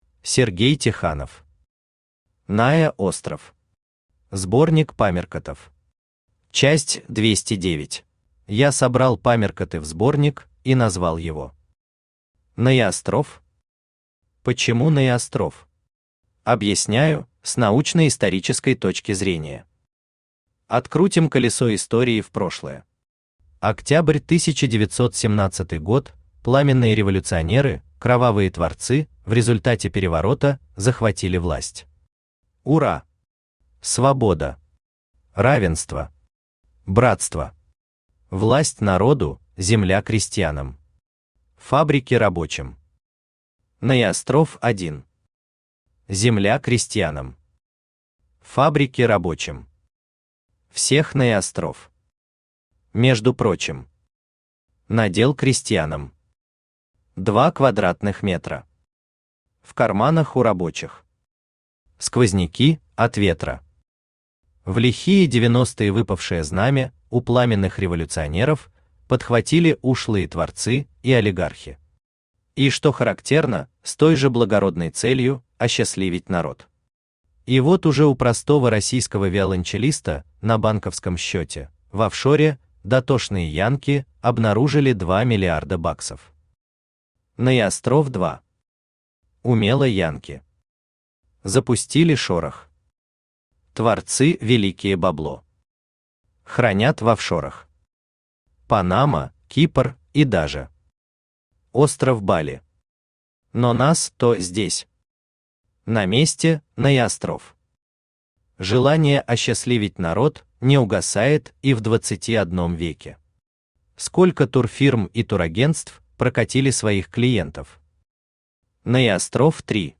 Аудиокнига НаеОстров. Сборник памяркотов. Часть 209 | Библиотека аудиокниг
Читает аудиокнигу Авточтец ЛитРес.